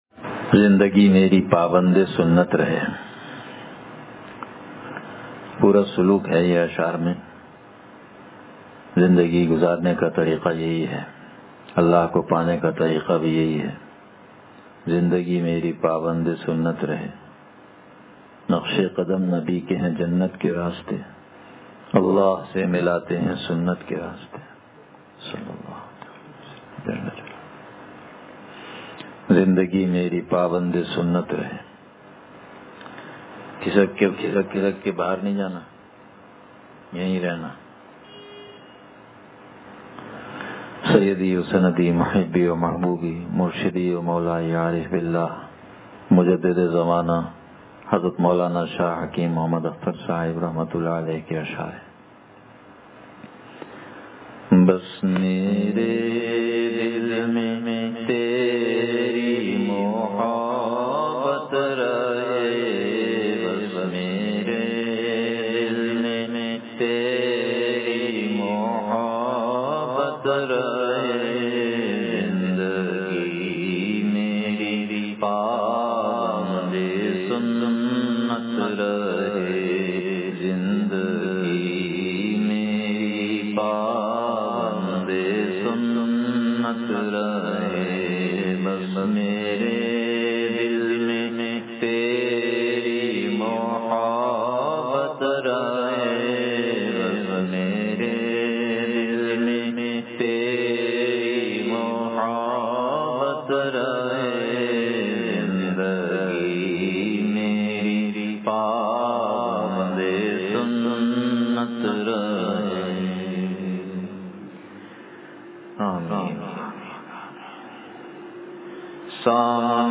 Bayan (m)